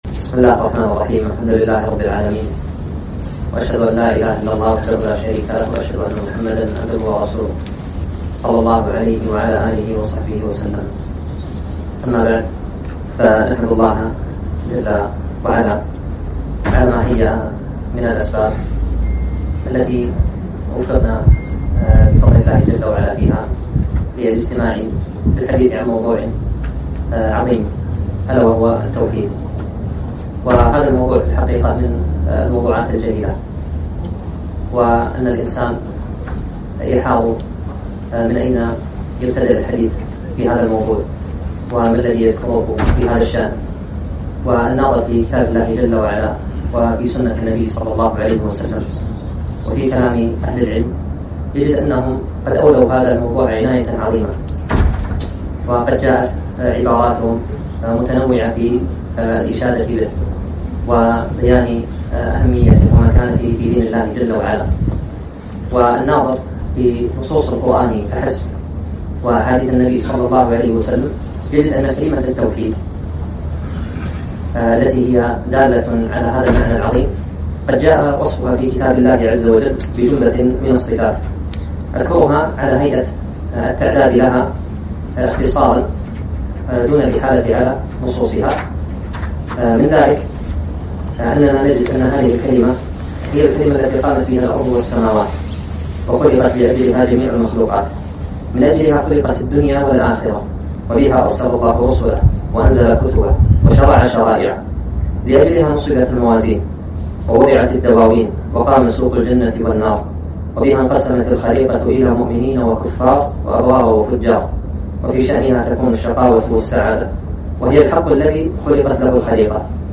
محاضرة - أهمية التوحيد